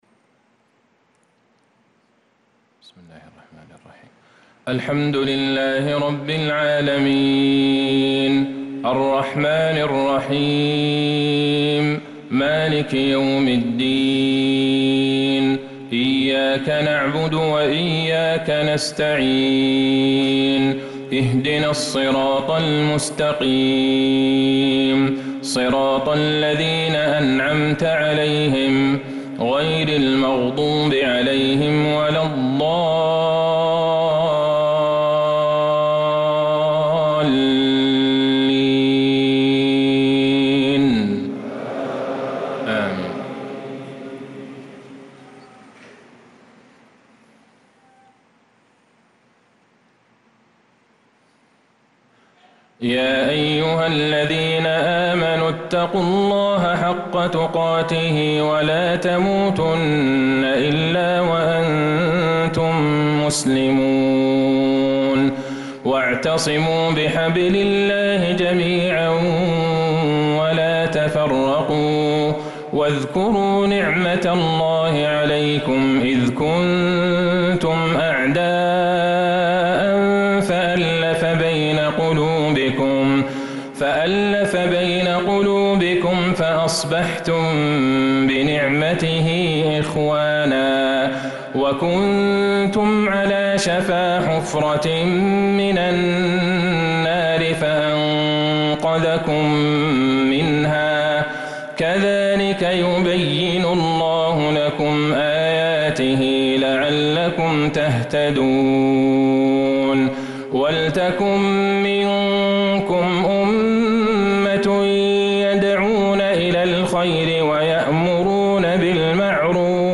صلاة العشاء للقارئ عبدالله البعيجان 19 محرم 1446 هـ